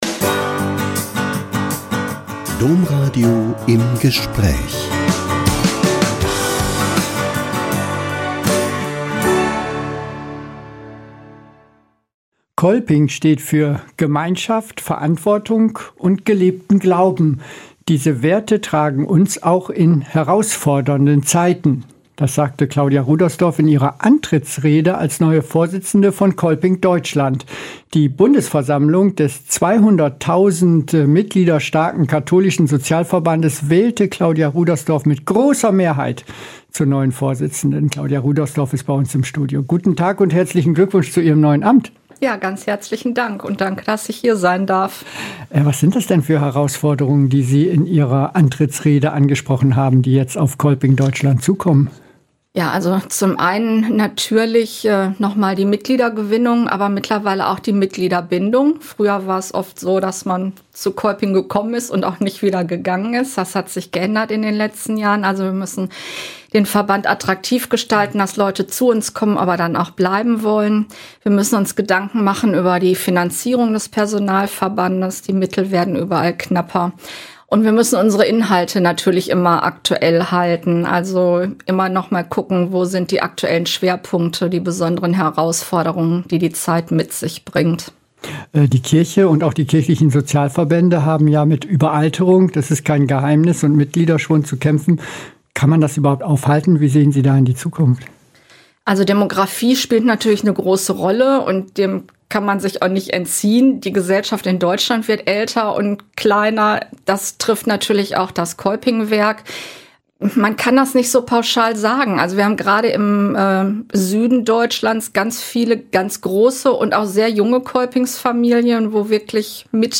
Interview erklärt sie, wie Kolping im digitalen Zeitalter relevant